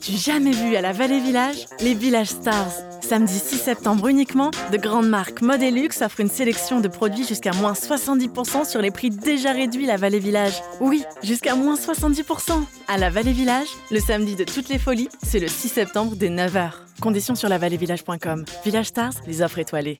Voix Off Publicité : La Vallée Village - Diffusion Radio
Timbre médium, medium grave.